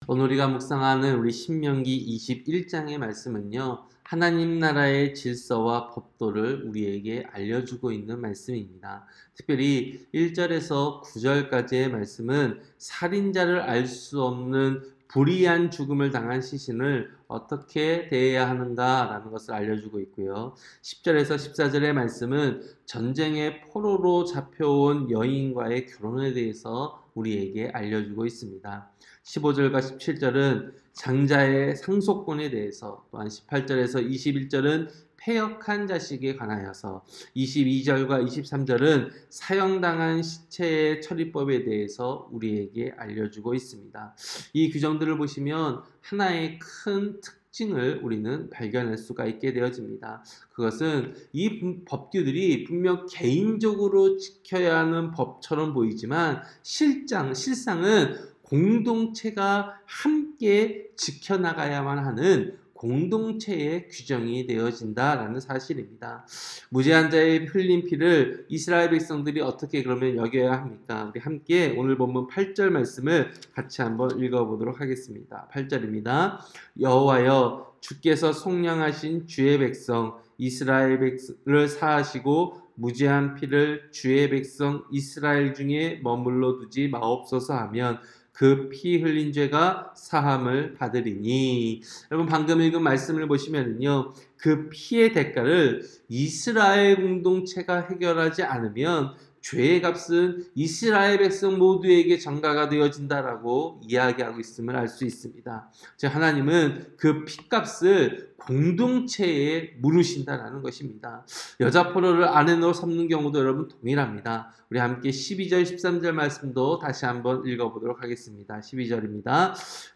새벽설교-신명기 21장